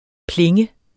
Udtale [ ˈpleŋə ]